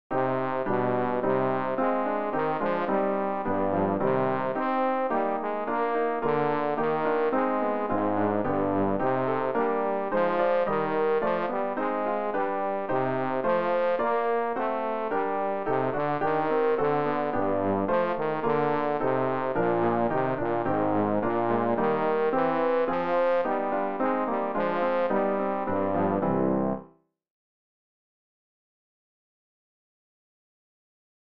Bass - EG 344 - Vater unser im Himmelreich.mp3